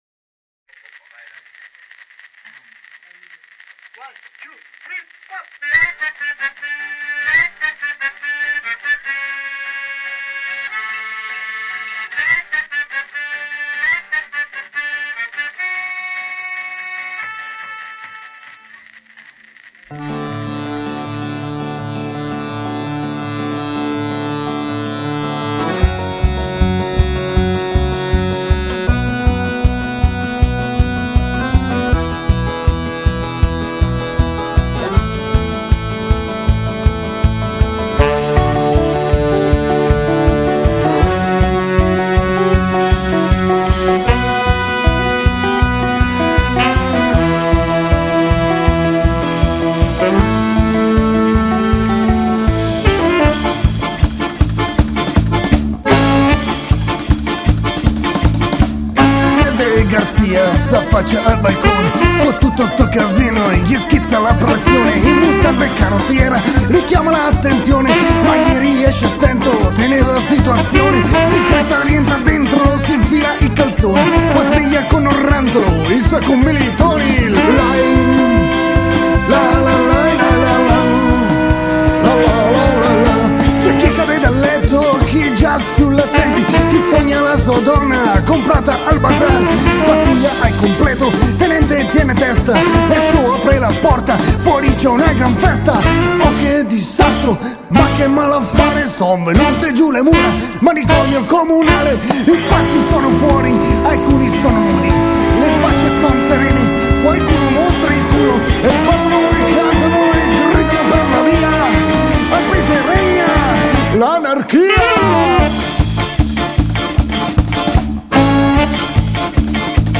Il disco è stato registrato nello studio di registrazione